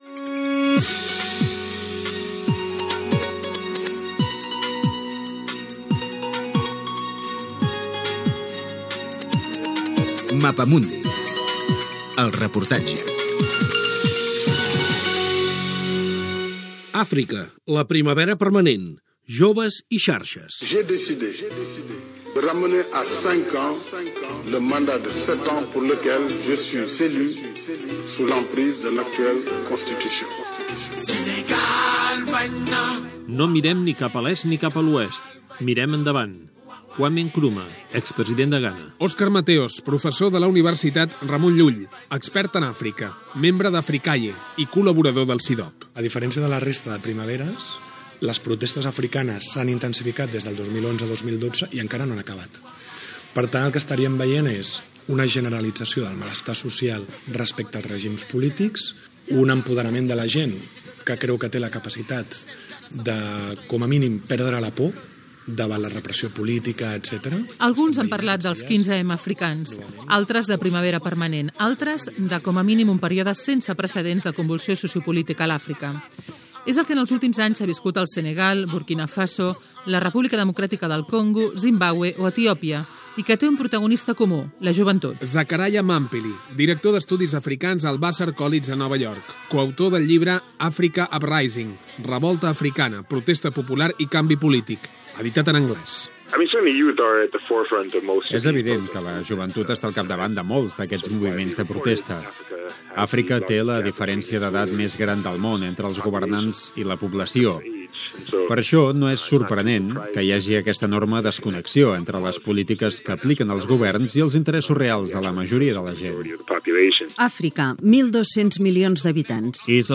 Careta del programa.
Informatiu
FM